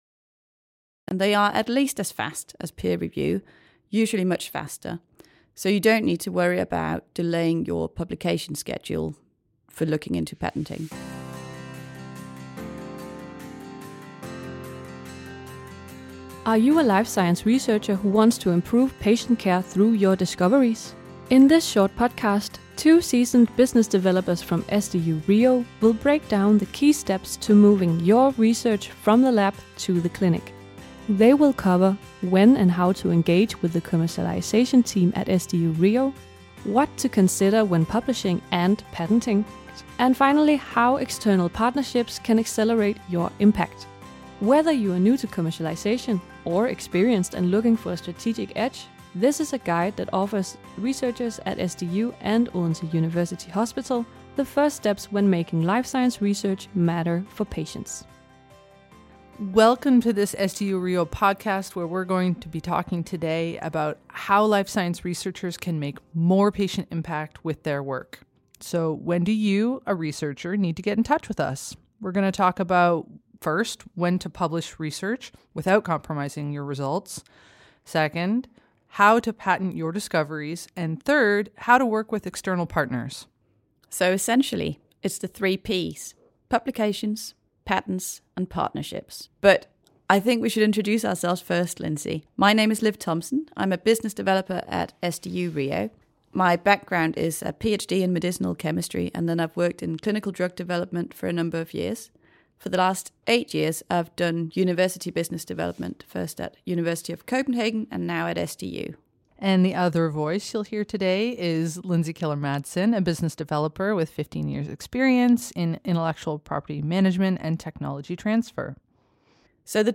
In this short podcast, two seasoned business developers from SDU and OUH will break down the key steps to moving YOUR research from the lab to the clinic.